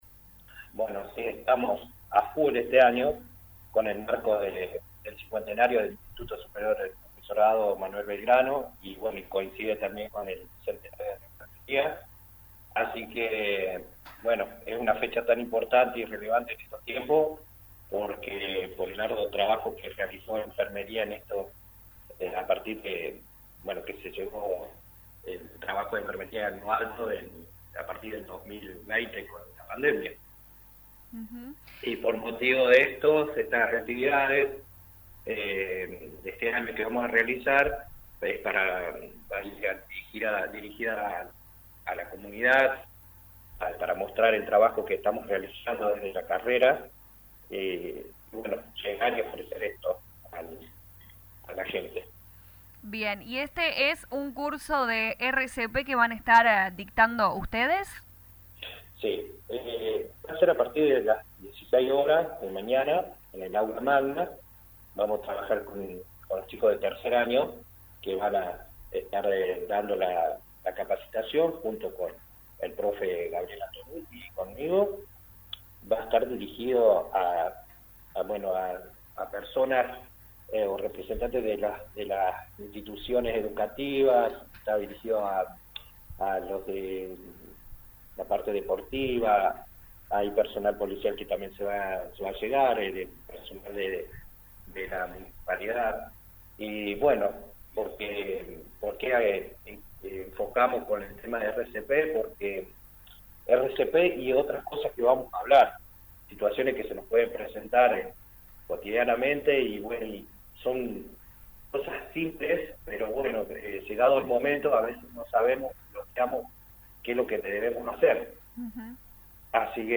En diálogo con LA RADIO 102.9 FM